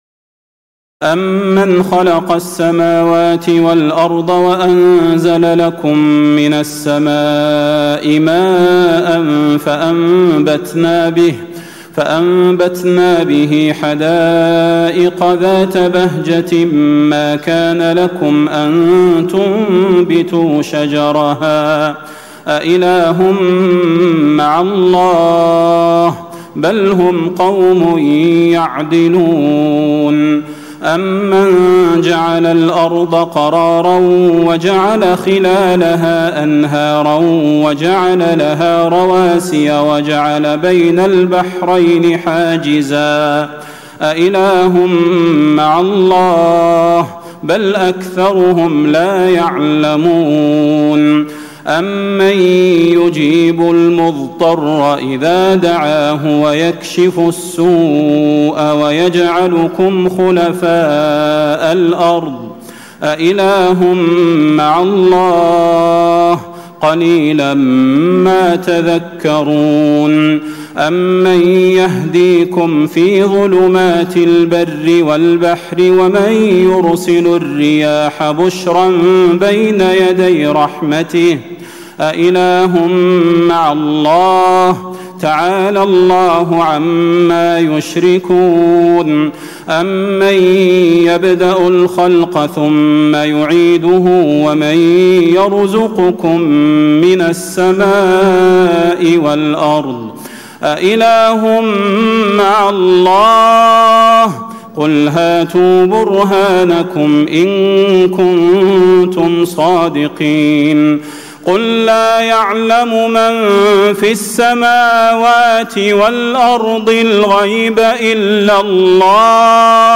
تراويح الليلة التاسعة عشر رمضان 1436هـ من سورتي النمل(60-93) و القصص(1-50) Taraweeh 19 st night Ramadan 1436H from Surah An-Naml and Al-Qasas > تراويح الحرم النبوي عام 1436 🕌 > التراويح - تلاوات الحرمين